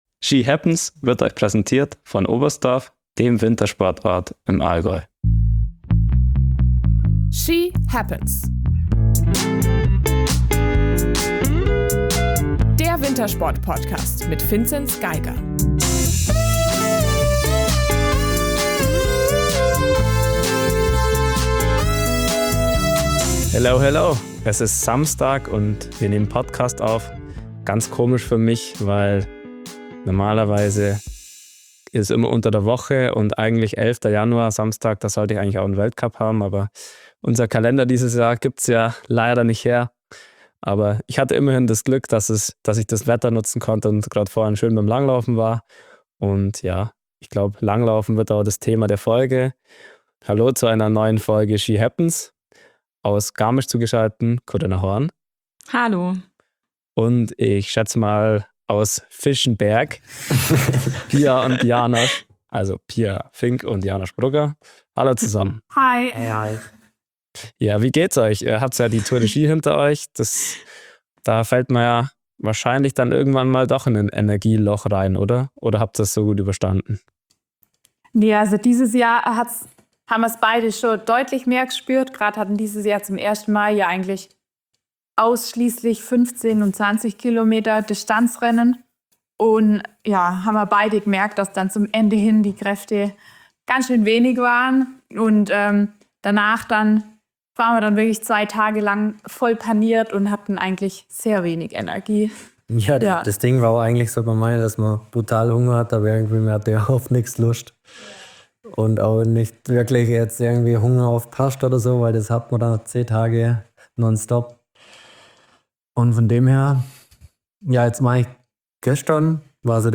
Mit den Besten aus Baden und Württemberg: Willkommen zurück Pia Fink und Janosch Brugger! 👋
Dieser und anderen kleinen und großen, aktuellen Langlauf-Fragen gehen wir auf den Grund. Mit dabei: eines von Deutschlands sympathischsten Cross-Country-Couples (dass es da mal noch nen Plural braucht… naja, wir haben NICHT nach Massagen gefragt) und seine Kuckucksuhr.